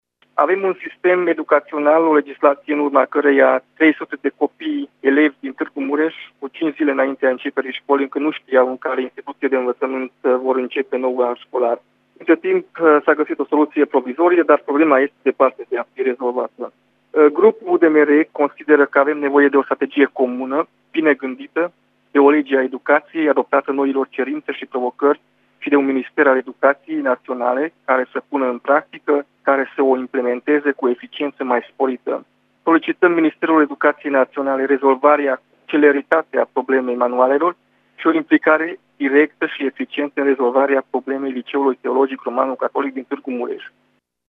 Senatorul UDMR Novak Csaba a afirmat că este necesară o strategie bine gândită și o lege a Educației adaptată noilor cerințe. El a cerut, în cadrul ședinței de dezbatere, să fie rezolvate probleme precum situația Liceului teoretic Romano-Catolic de la Tg. Mureș: